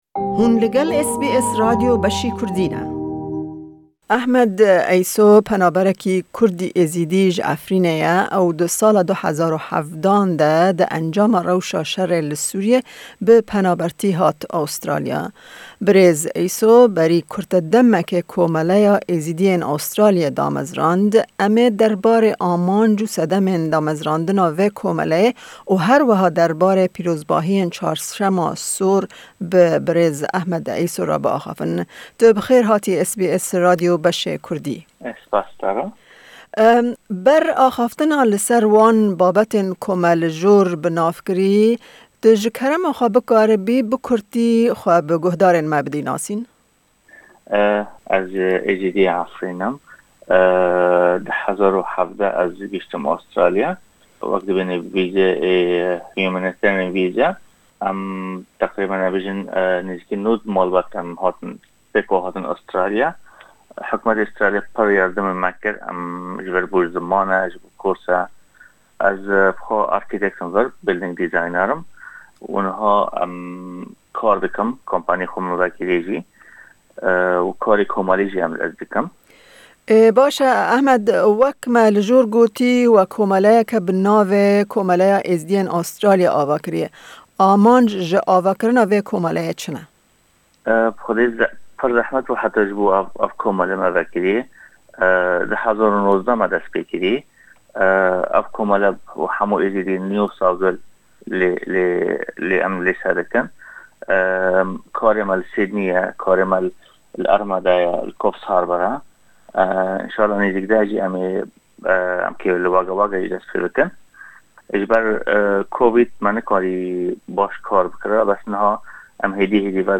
Di vê hevpeyvînê de